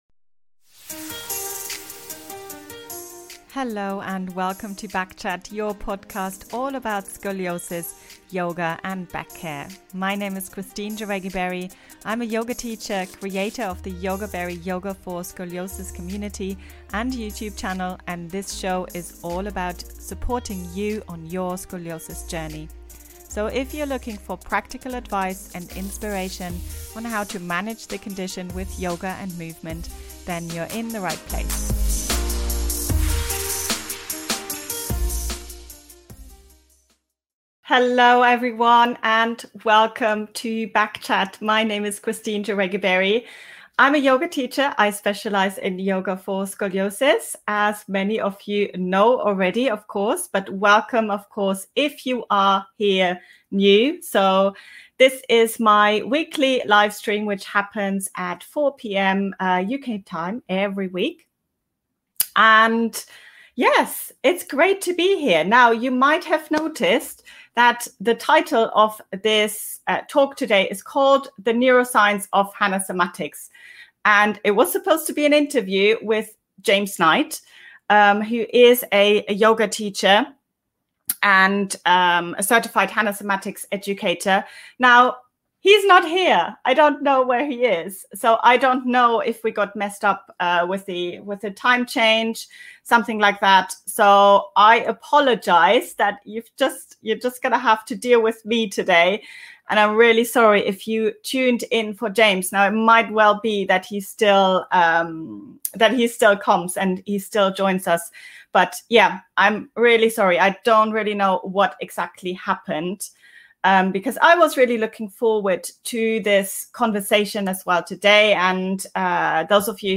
Scoliosis & Osteoporosis – Interview